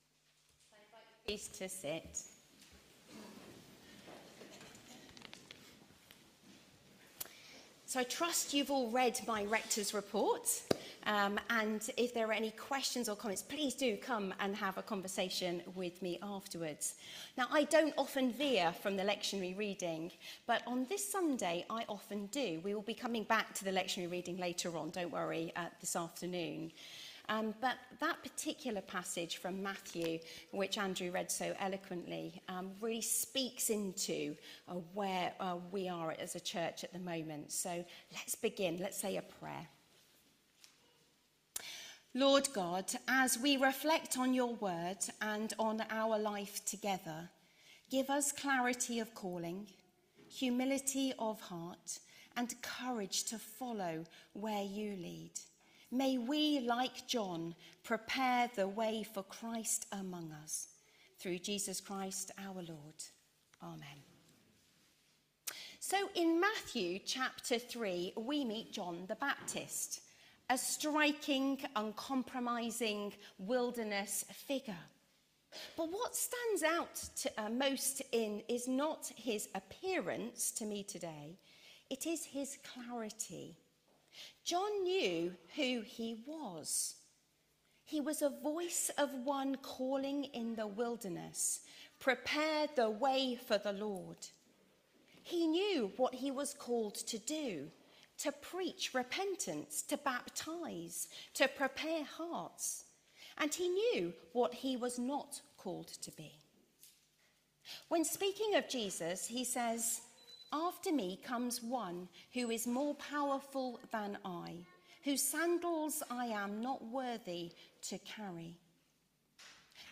HomeSermonsWe are signposts to the…